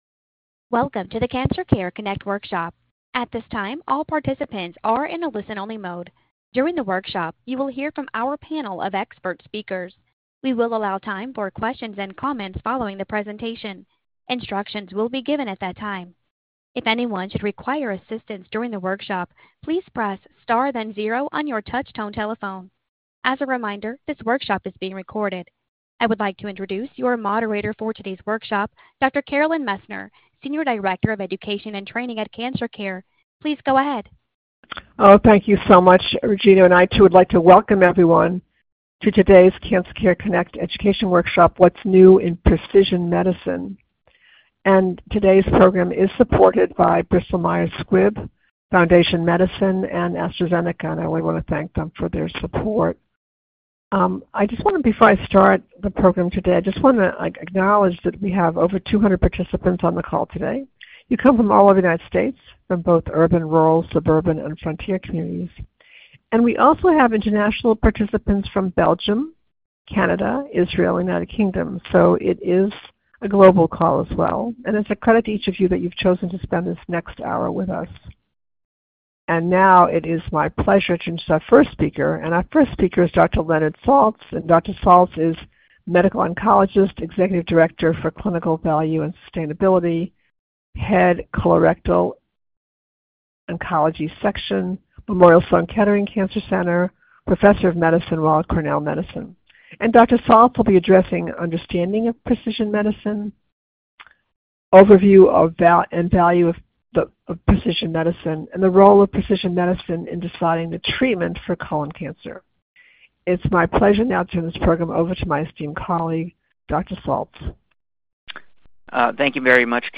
Questions for Our Panel of Experts